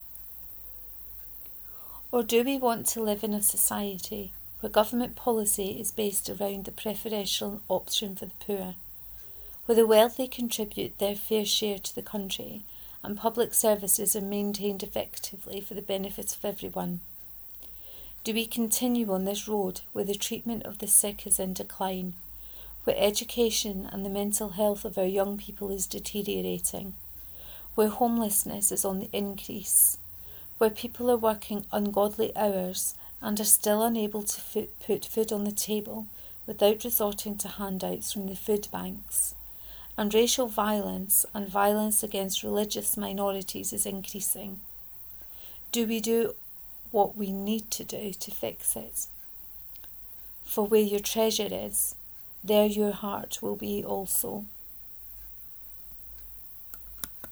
Shoving yer Grannie aff a bus 3 : reading of this post